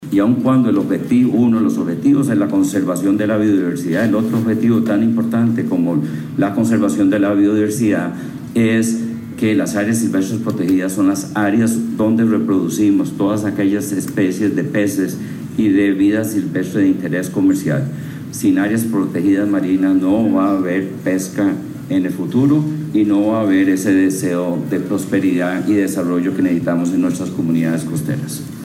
Rodríguez celebró en conferencia de prensa la iniciativa firmada por el mandatario y explicó cuáles son los objetivos que se persiguen para la conservación de los recursos naturales.